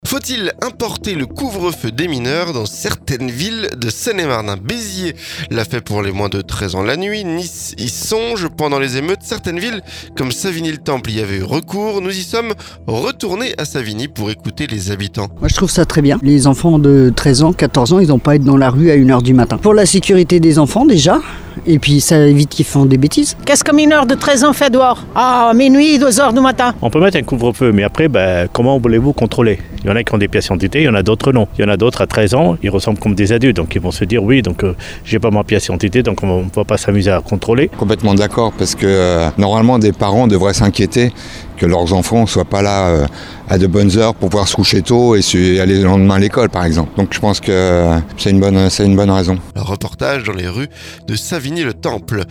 Béziers l'a fait pour les moins de 13 ans la nuit, Nice y songe... Pendant les émeutes, certaines villes comme Savigny-le-Temple l'avaient fait. Nous y sommes retournés pour écouter les habitants.